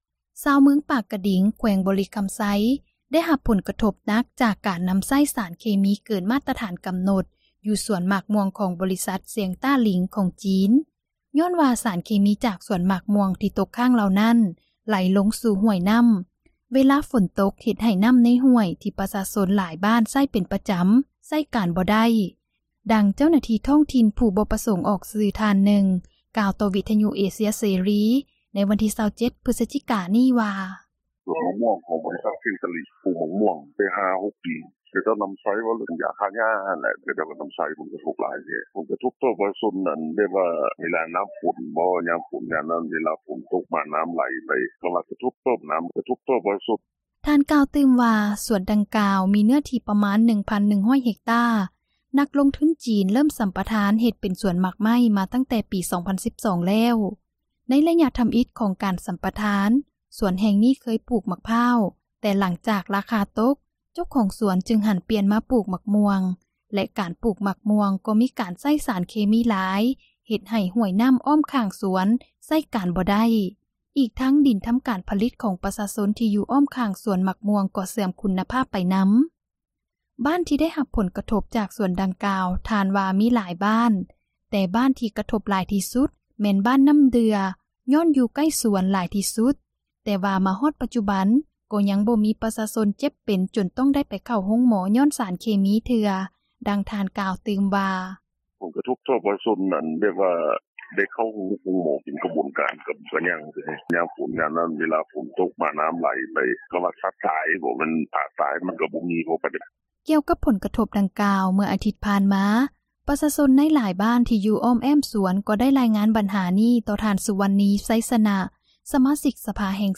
ຊາວເມືອງປາກກະດິງ ແຂວງບໍຣິຄຳໄຊ ໄດ້ຮັບຜົນກະທົບໜັກ ຈາກການນຳໃຊ້ສານເຄມີ ເກີນມາຕຖານກຳນົດ ຢູ່ສວນໝາກມ່ວງຂອງ ບໍຣິສັດ ຊຽງຕາລີ ຂອງຈີນ ຍ້ອນວ່າສານເຄມີ ຈາກສວນໝາກມ່ວງ ທີ່ຕົກຄ້າງເຫຼົ່ານັ້ນ ໄຫຼລົງສູ່ຫ້ວຍນໍ້າ ເວລາຝົນຕົກ ເຮັດໃຫ້ນໍ້າໃນຫ້ວຍ ທີ່ປະຊາຊົນຫຼາຍບ້ານໃຊ້ເປັນປະຈຳ ໃຊ້ການບໍ່ໄດ້, ດັ່ງເຈົ້າໜ້າທີ່ ທ້ອງຖິ່ນຜູ້ບໍ່ປະສົງອອກຊື່ ທ່ານນຶ່ງ ກ່າວຕໍ່ວິທຍຸເອເຊັຍເສຣີ ໃນວັນທີ່ 27 ພຶສຈິການີ້ວ່າ: